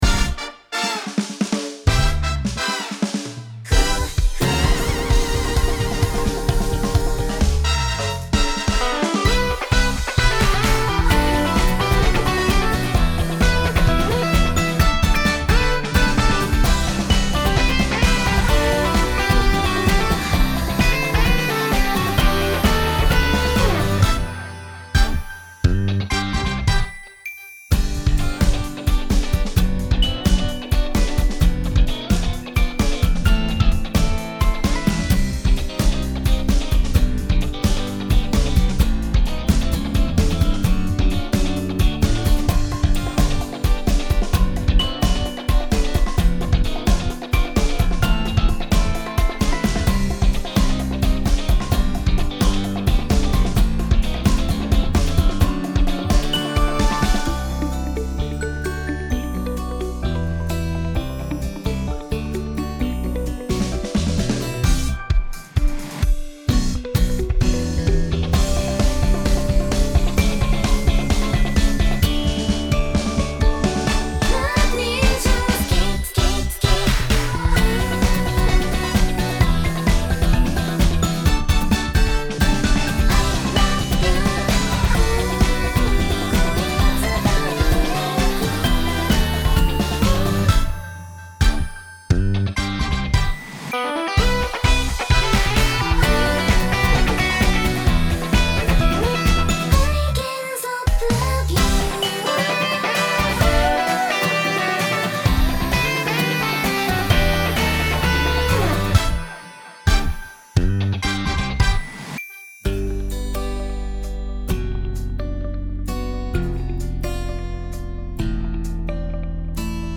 ボーカルだけが抜かれており、コーラスは入っております。
エレキギター
爽快
エレキベース
明るい
ポップ
ドラム